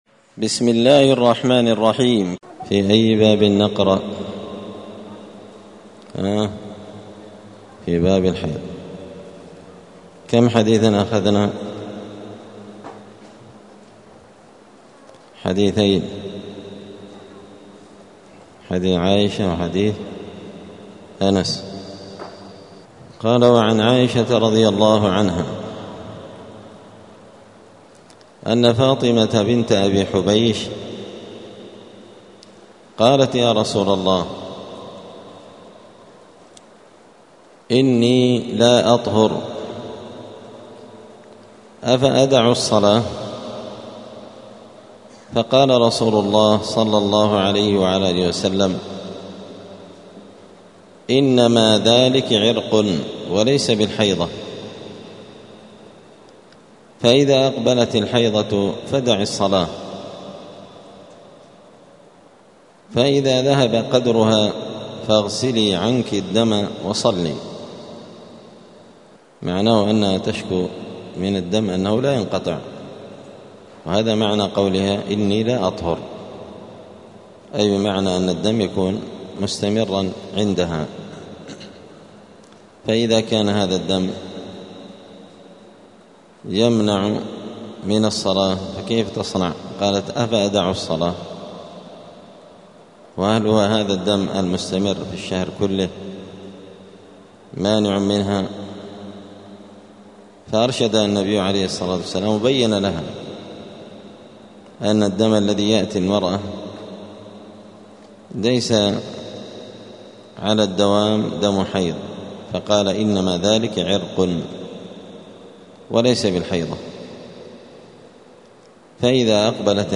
دار الحديث السلفية بمسجد الفرقان قشن المهرة اليمن
*الدرس السابع والتسعون [97] {باب الحيض الفرق بين دم الحيض والاستحاضة وحكم المستحاضة}*